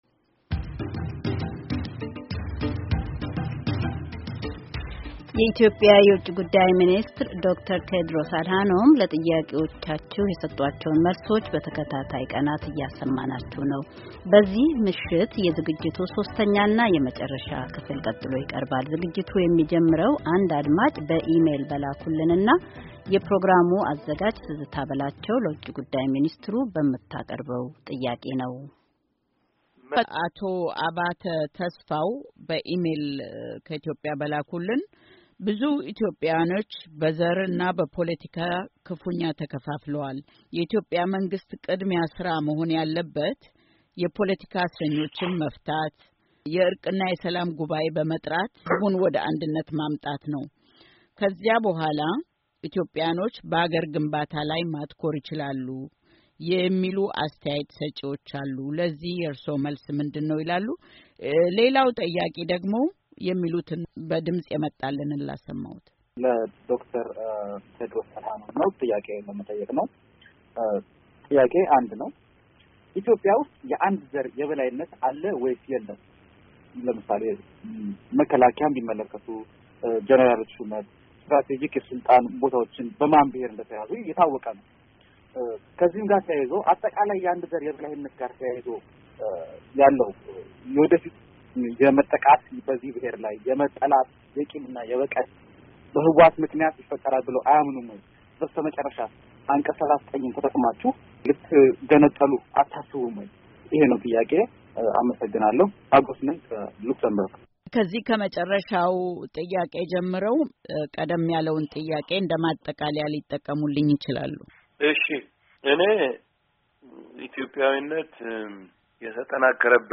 ክፍል 3፡ የኢትዮጲያ ውጭ ጉዳይ ሚኒስትር ለአሜሪካ ድምፅ ራድዮ የሰጡት ቃለ-ምልልስ
የኢትዮጵያ ዉጭ ጉዳይ ሚኒስትር ዶ/ር ቴዎድሮስ አድሃኖም በልዩ ልዩ ወቅታዊ ጉዳዮች ላይ ከአሜሪካ ድምፅ ራድዮ ጋር ተወያይተዋል።